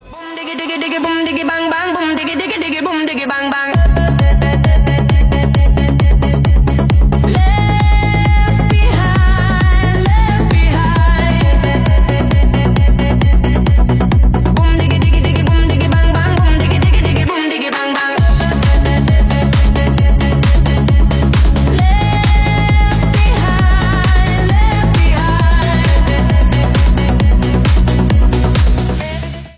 Заряженный и бодрый рингтон с мощным битом.
Танцевальные рингтоны